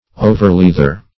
Overleather \O"ver*leath`er\, n.
overleather.mp3